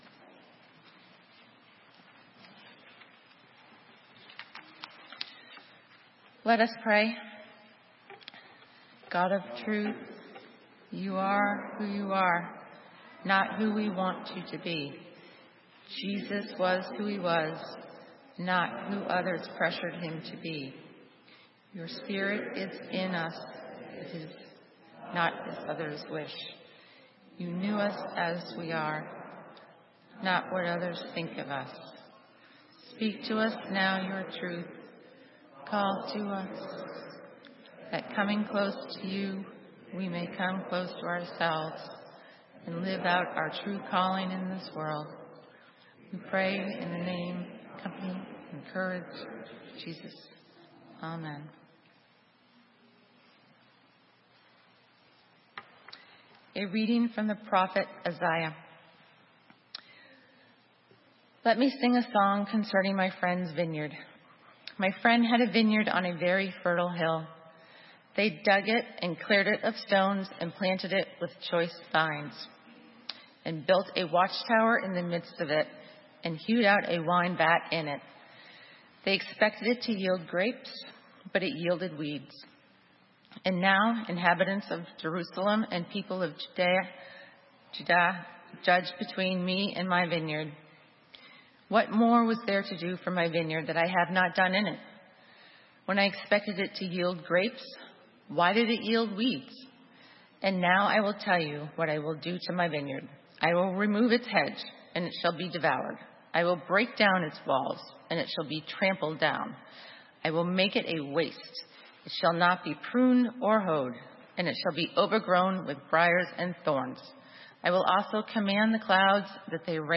Sermon:You, not their idea of you - St. Matthews United Methodist Church